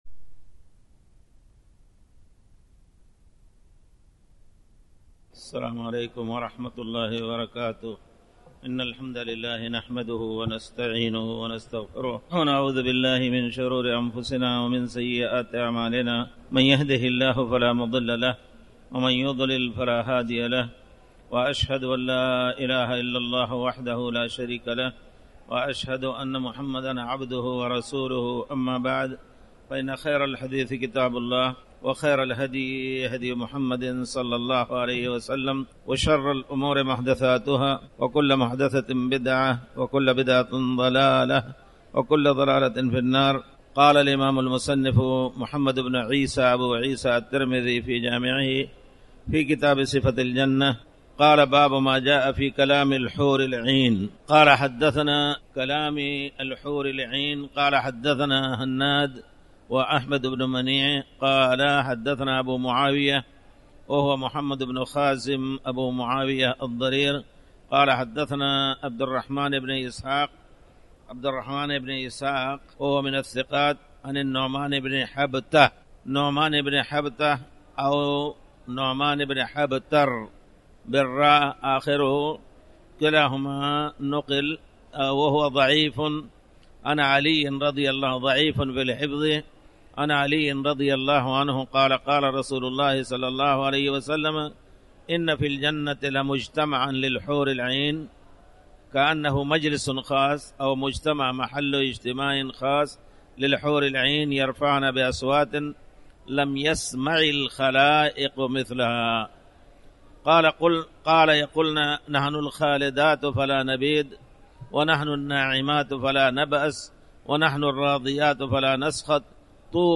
تاريخ النشر ٥ رجب ١٤٣٩ هـ المكان: المسجد الحرام الشيخ